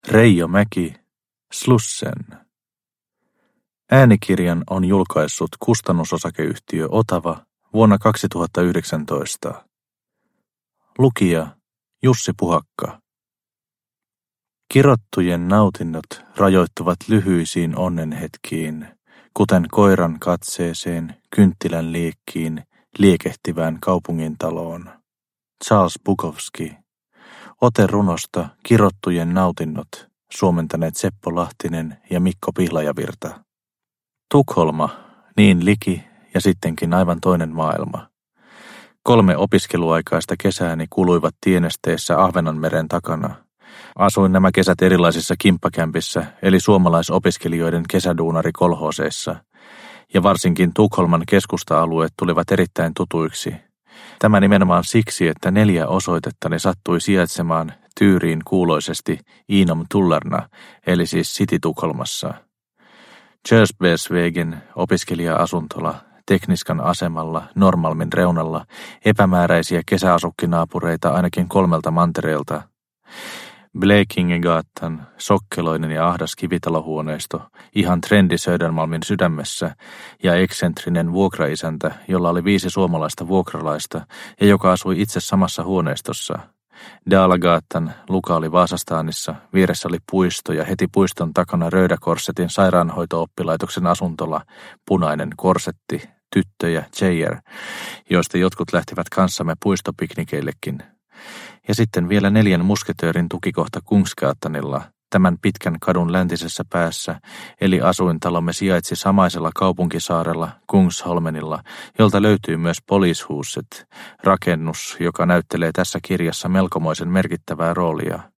Slussen – Ljudbok – Laddas ner